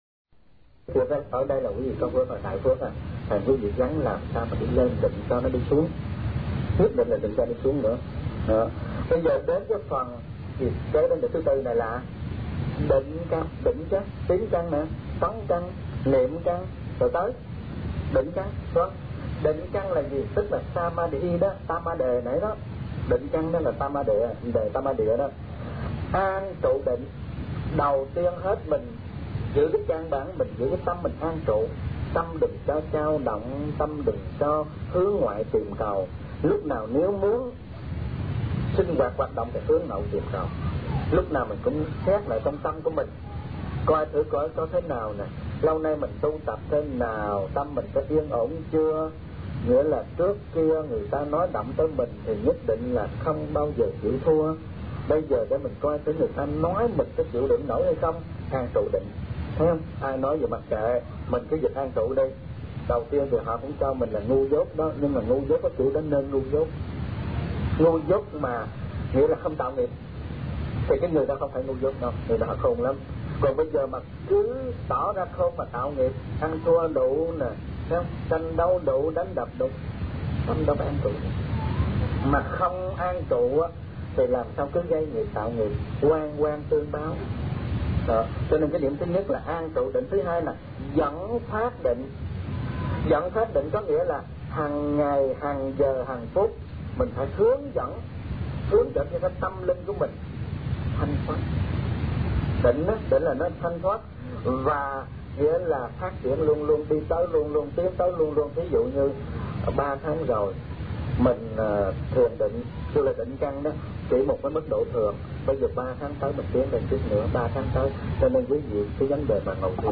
Kinh Giảng Tứ Diệu Đế